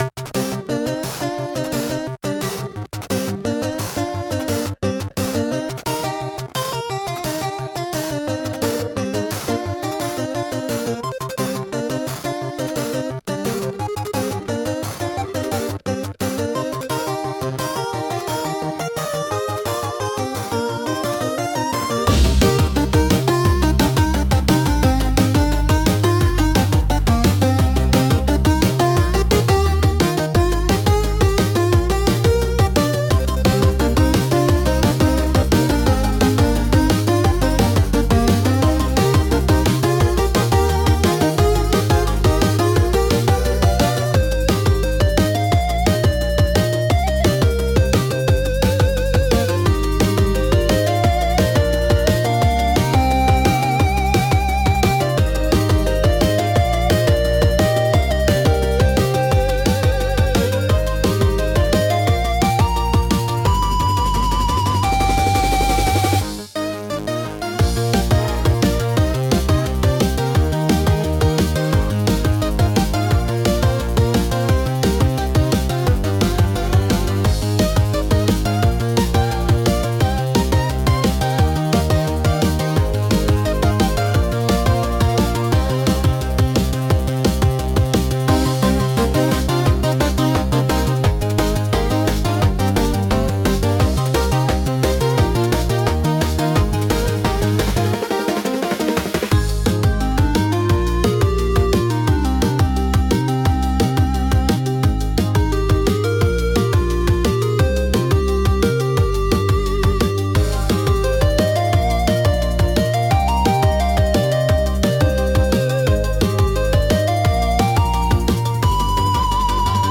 Genre: Electronic Mood: Video Game Editor's Choice